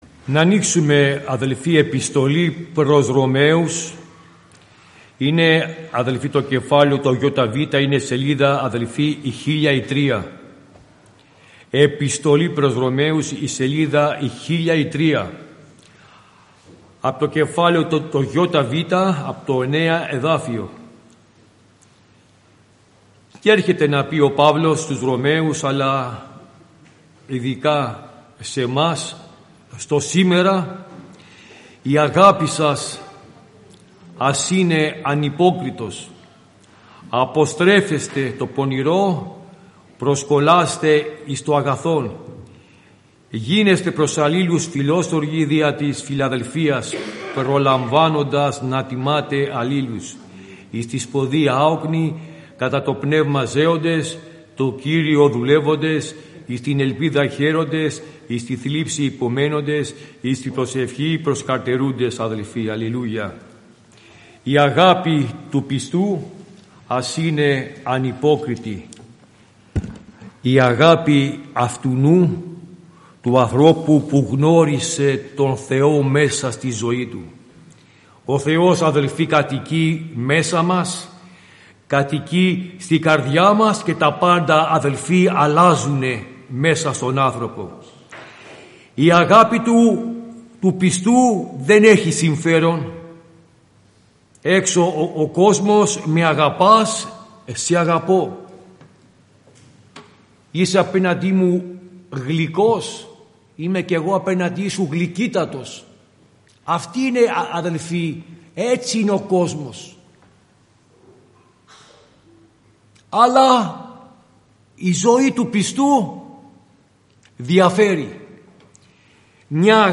Κηρύγματα 2025 Ημερομηνία